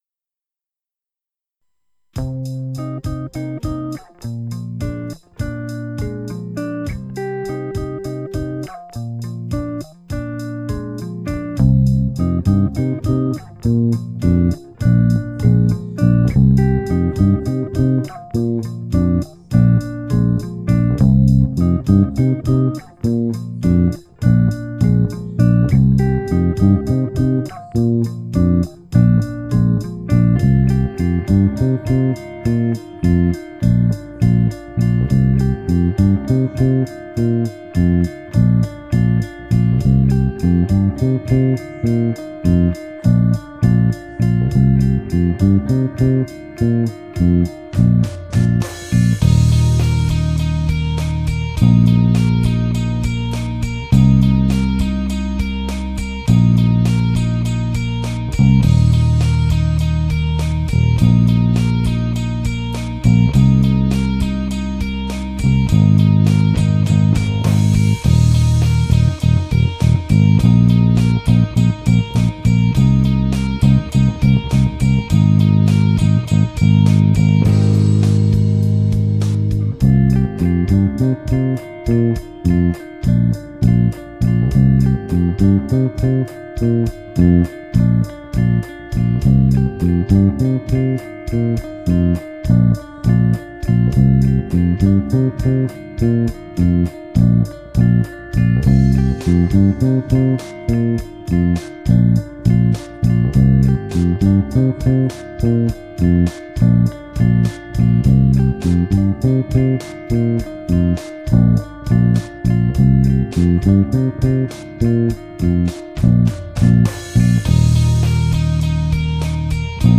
instrumental
Bass only